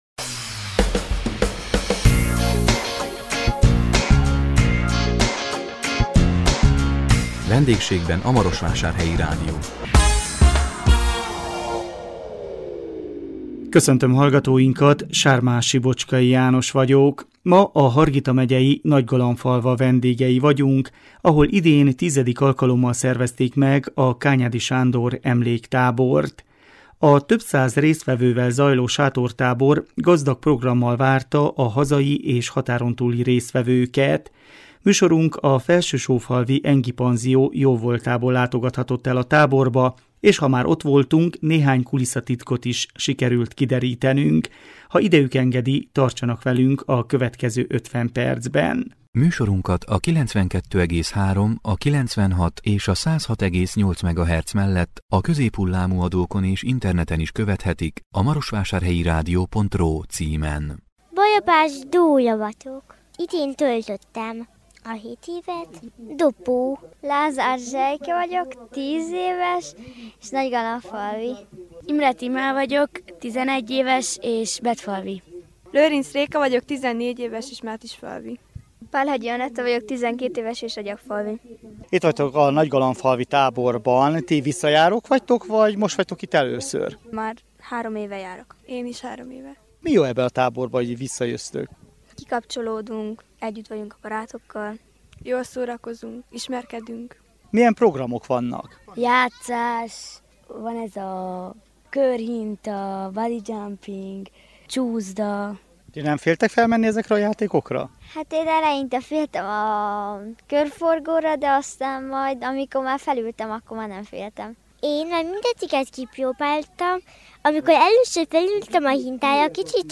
A 2019 július 25-én jelentkező Vendégségben a Marosvásárhelyi Rádió című műsorunkban a Hargita megyei Nagygalambfalva vendégei voltunk, ahol idén X. alkalommal szervezték meg a Kányádi Sándor emléktábort. A több száz résztvevővel zajló sátortábor gazdag programmal várta a hazai és határon túli résztvevőket.